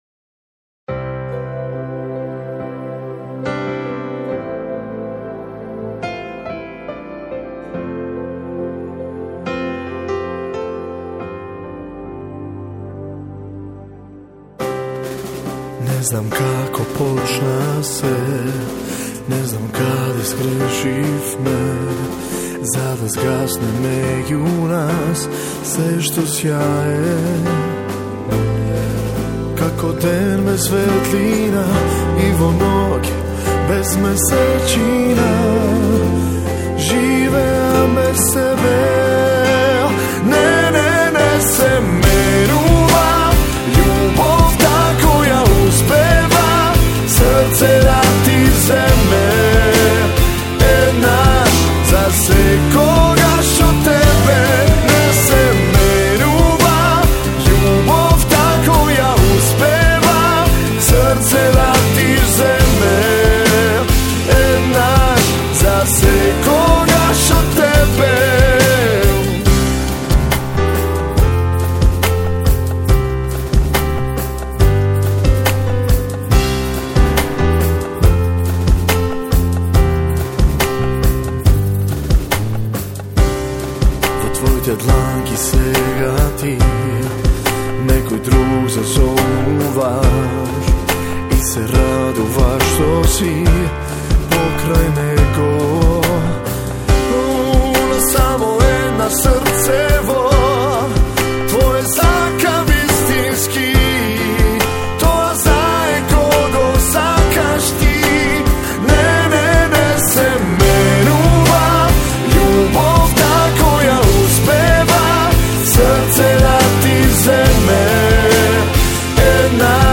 баладата
така и со фантастичната вокална изведба.
/ save target as..) и уживајте во преубавата балата!!